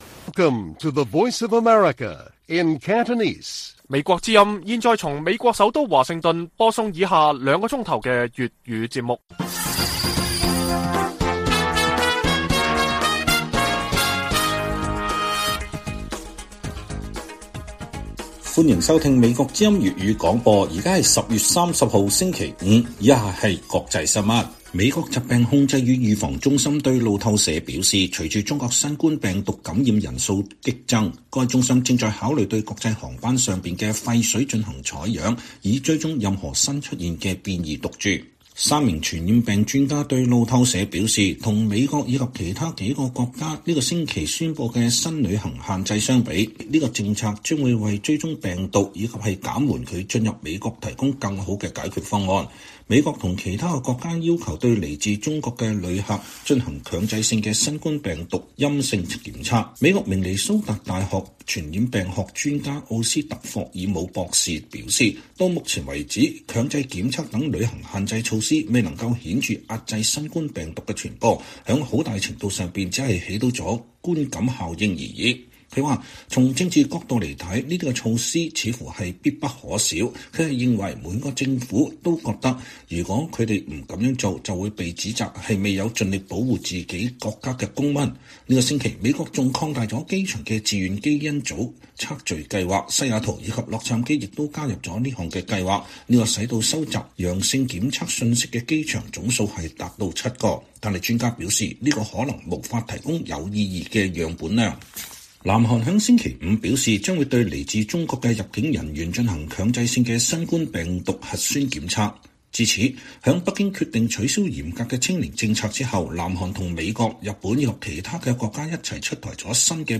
粵語新聞 晚上9-10點: 美國考慮對國際航班廢水進行新冠病毒檢測